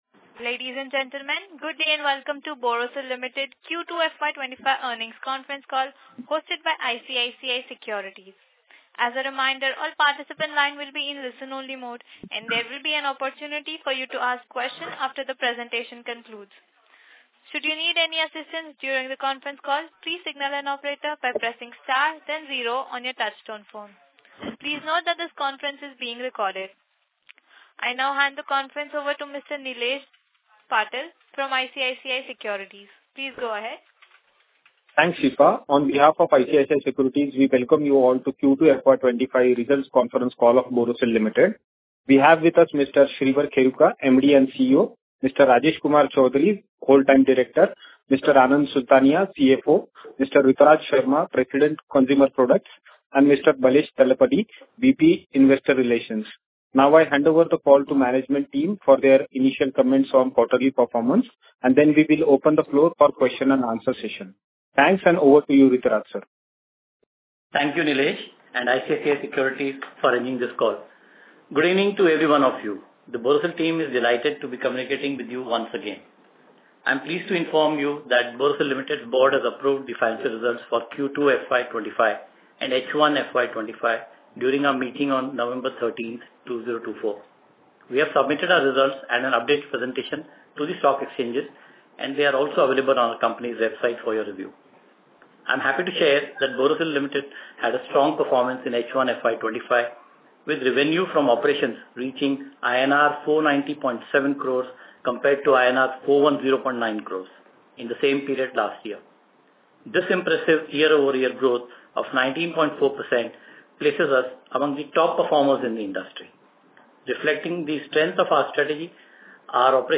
Analyst call recording - May 28, 2024